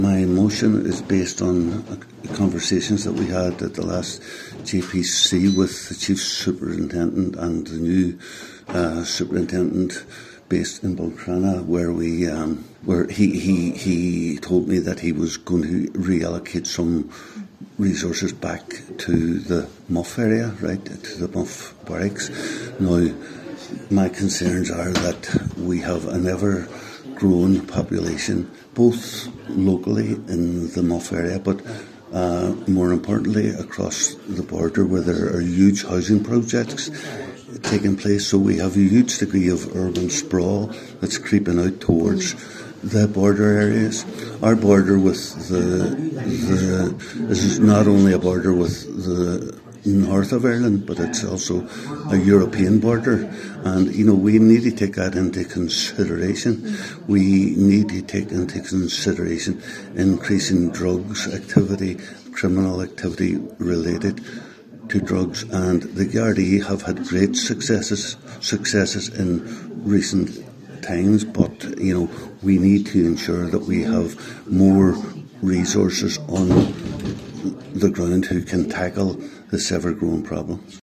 Cllr Terry Crossan and Cllr Albert Doherty moved similar motions at a plenary meeting of Donegal County Council pertaining to the greater need for garda services in the area.
Cllr Crossan highlighted the effects of urban sprawl in West Donegal, saying one such impact is the clear increase in drug use and criminality: